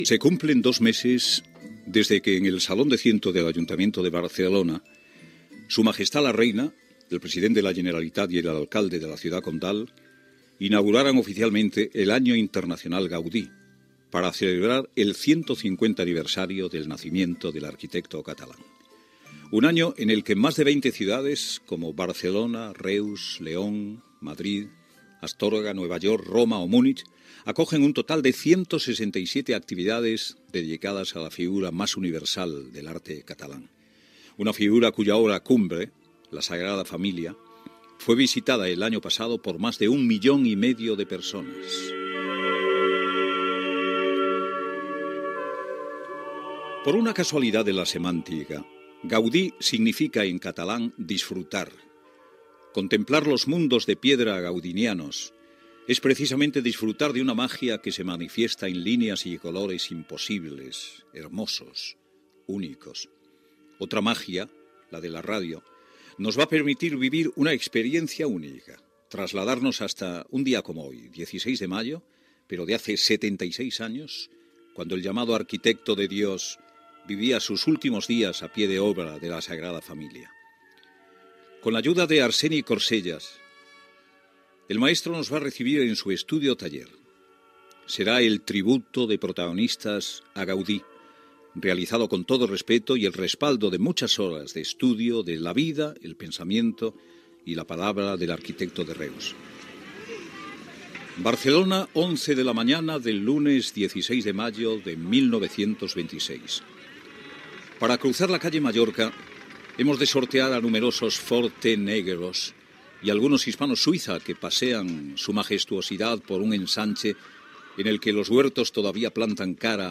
Presentació i entrevista fictícia a l'arquitecte Antoni Gaudí.
Info-entreteniment